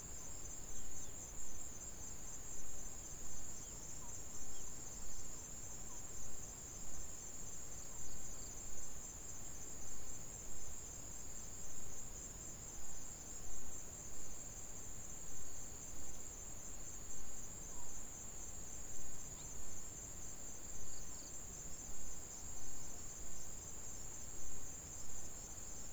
field_evening.ogg